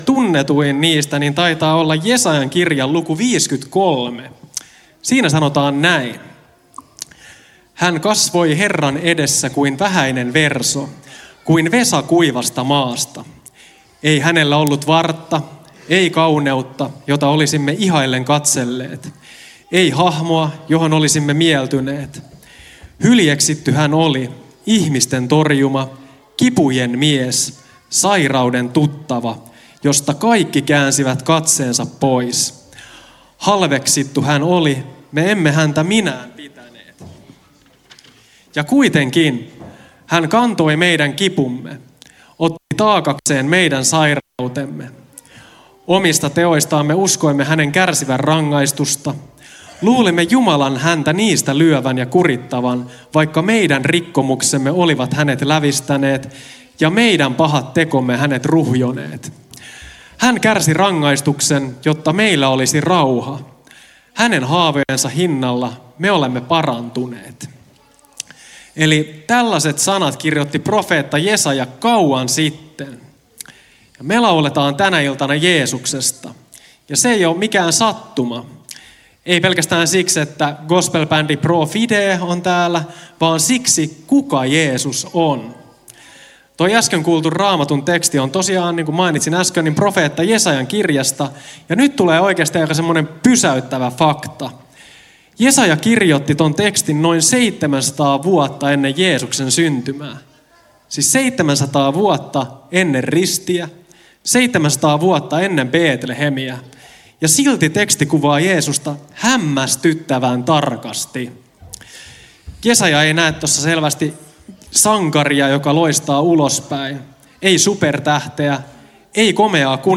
yhteispuhe Lohtajalla perjantain Majatalo- ja konserttihetkessä